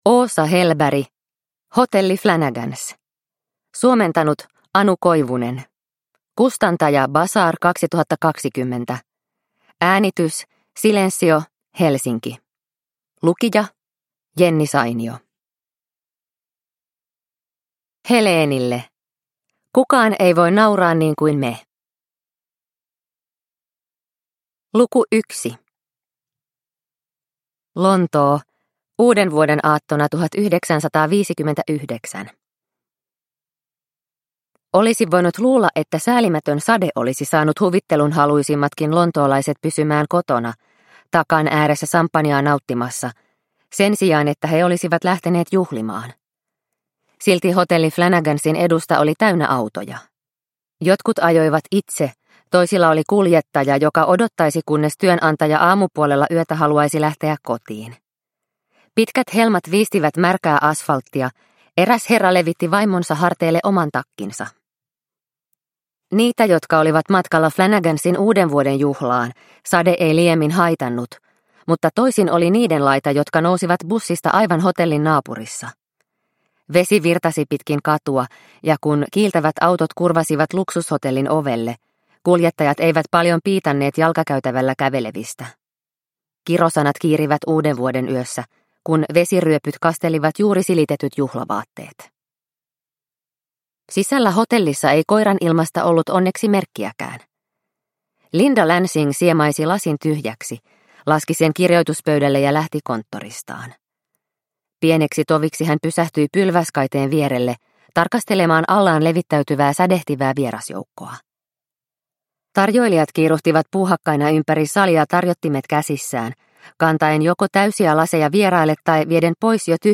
Hotelli Flanagans – Ljudbok – Laddas ner